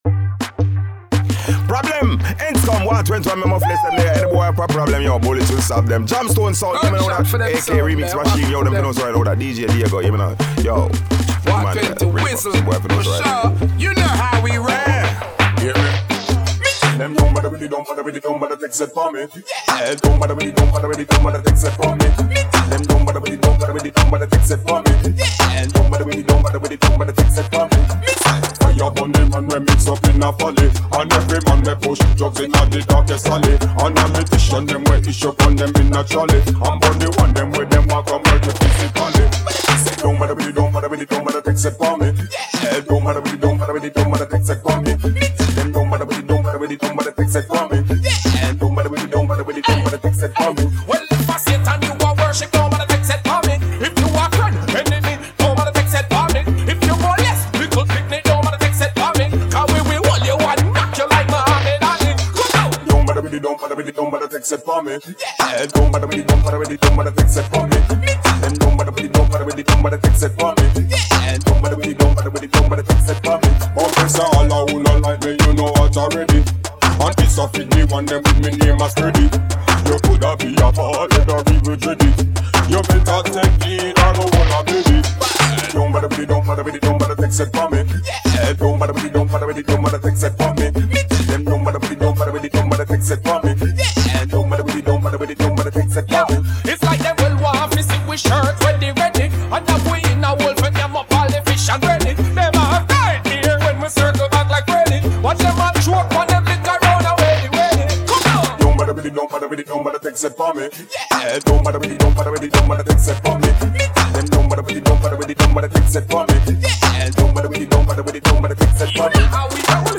Vocal track
riddim